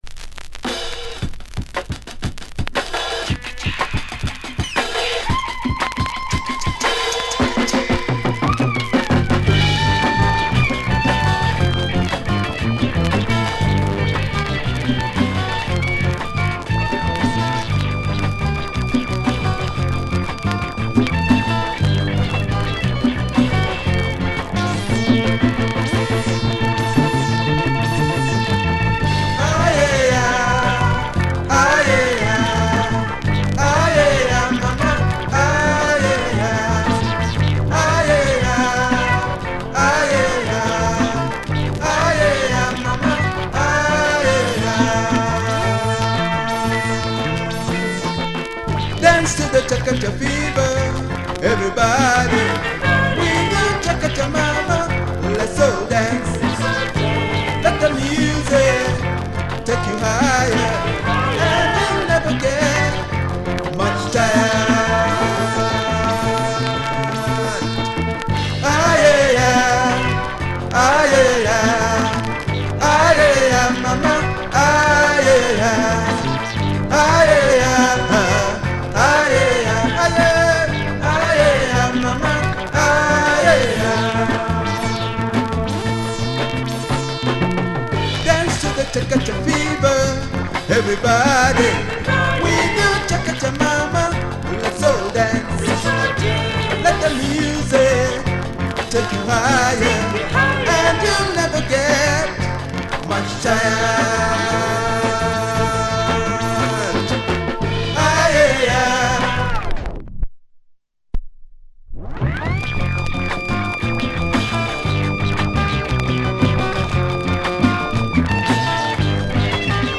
catchy Afro POP number with plenty of moog